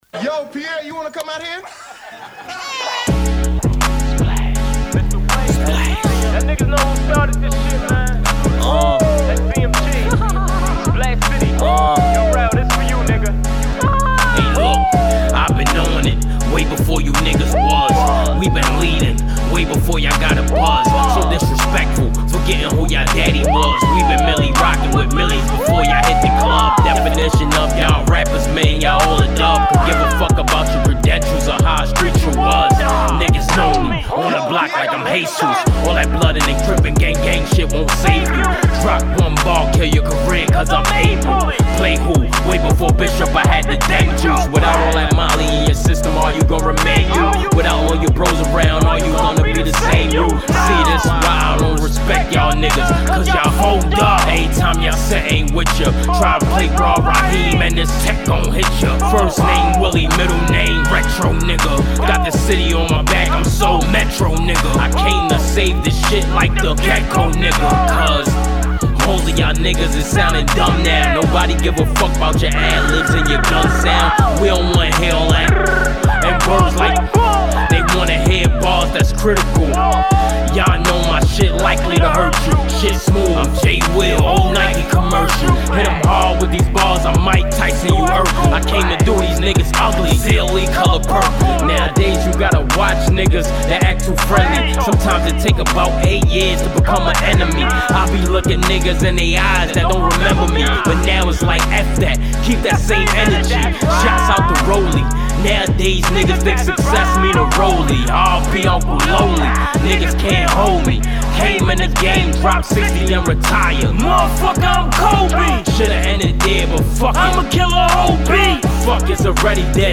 Hiphop
Description : Dope Freestyle